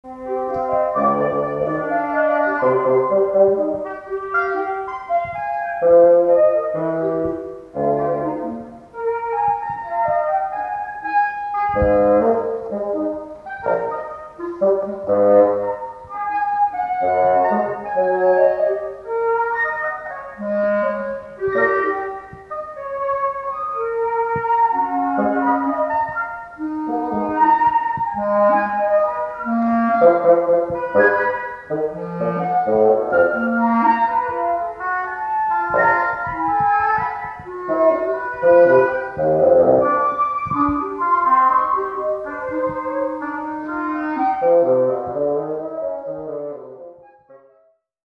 concerto dal vivo
audio 44kz stereo